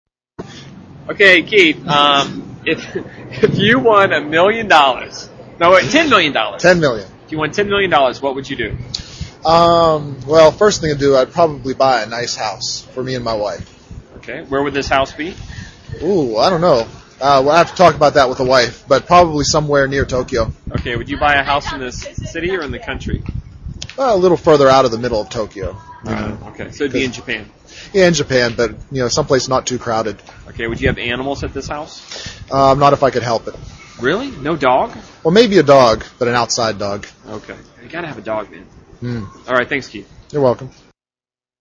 英语高级口语对话正常语速01：一百万美元（MP3）